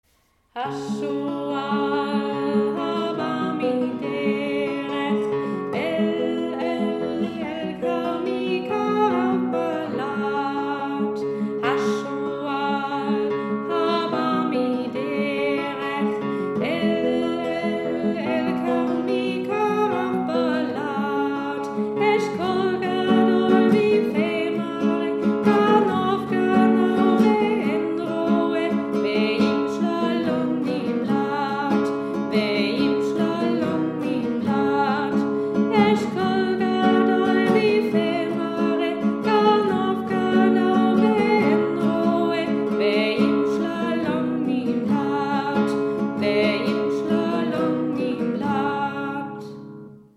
Aussprache: Die in Klammern gesetzten Laute werden ausgesprochen, aber im Hebräischen nicht geschrieben. Übersetzung des Textes: Der Fuchs nähert sich langsam vom Wege her meinem Weinberg.
Lied Update needed